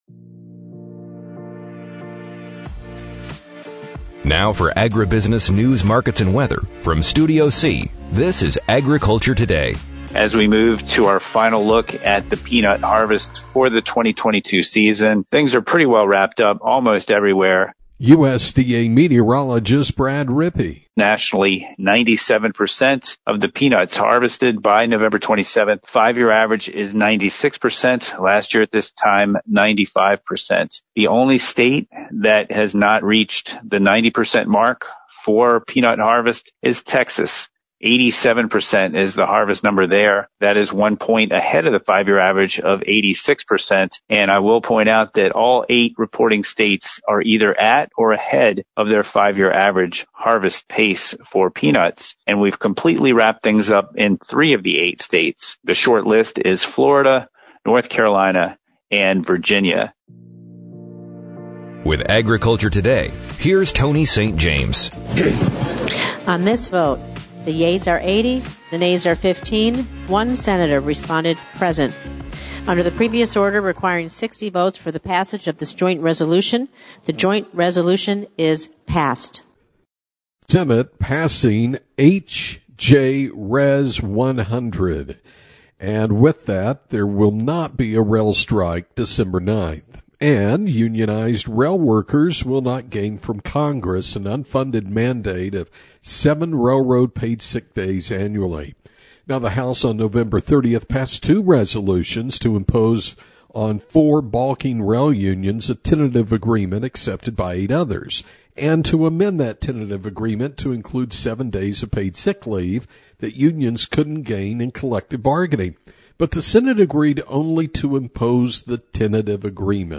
AGRICULTURE TODAY PODCAST: (from the Amarillo Farm and Ranch Show) Today’s episode looks at a resolution to the rail strike, cotton research, rural broadband, and hemp.